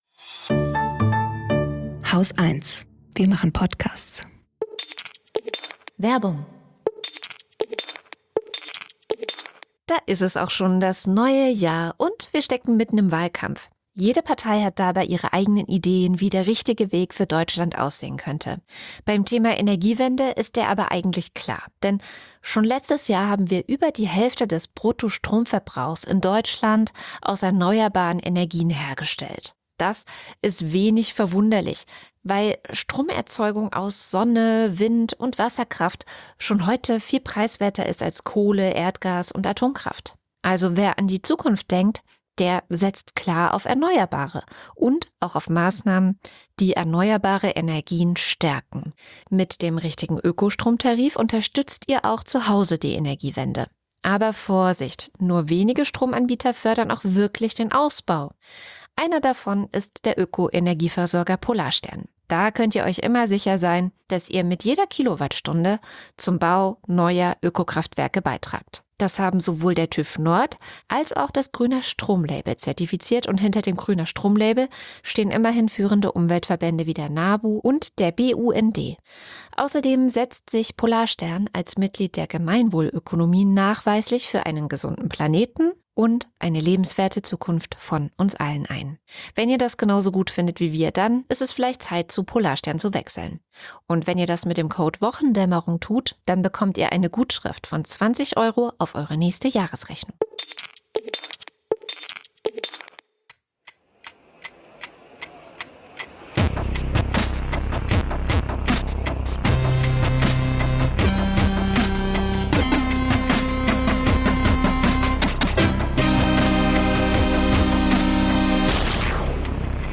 Gesprächspodcast
News Talk